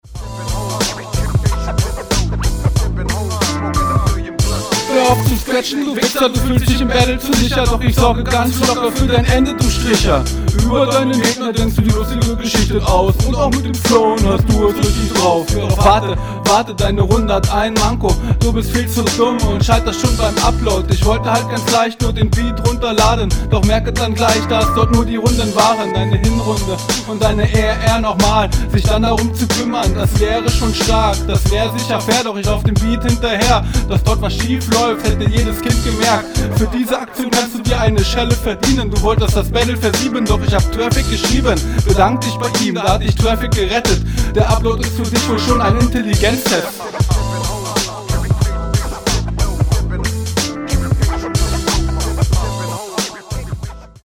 Soundquali wirklich nicht gut. Deine Doubles passen öfter nicht. Manchmal etwas Offbeat.
Abmische grausam, man versteht kaum was.